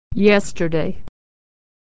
Consonant Sound /y/ - Pattern - Authentic American Pronunciation
Consonant Sound Voiced /y/
/y/ is always followed by a vowel sound
y-yesterday.mp3